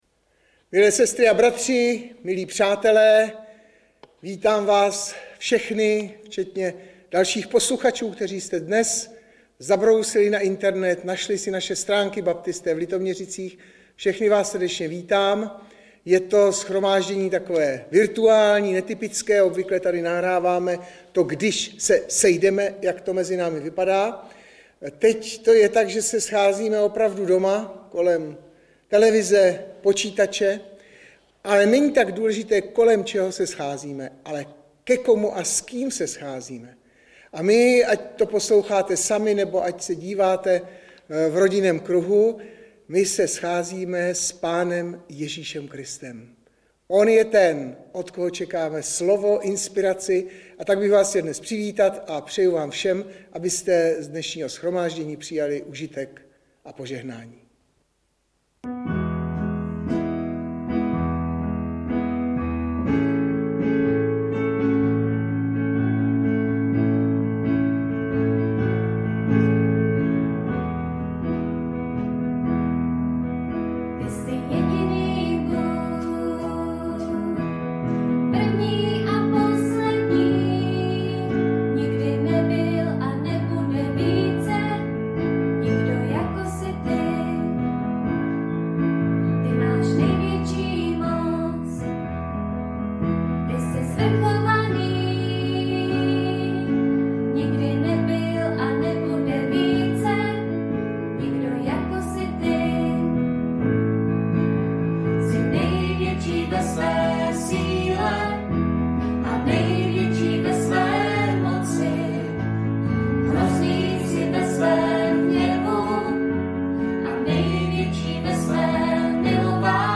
Webové stránky Sboru Bratrské jednoty v Litoměřicích.
Audiozáznam kázání si můžete také uložit do PC na tomto odkazu.